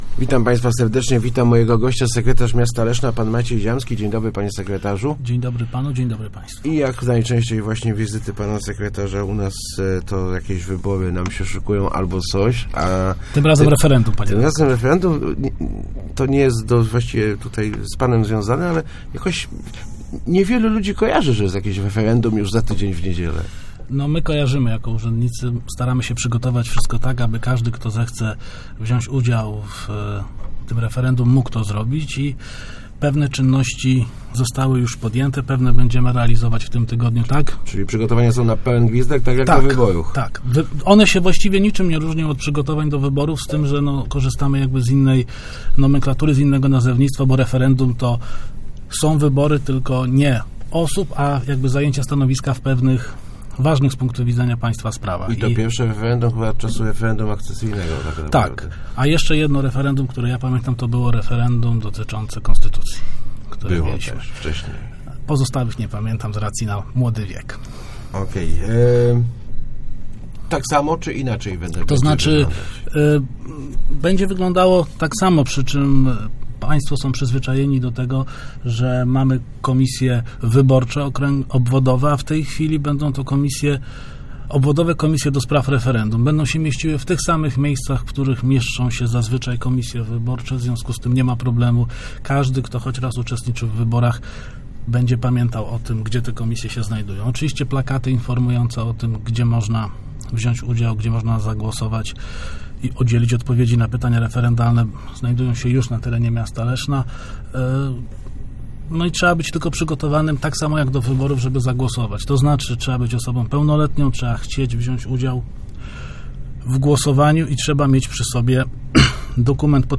Start arrow Rozmowy Elki arrow W niedzielę referendum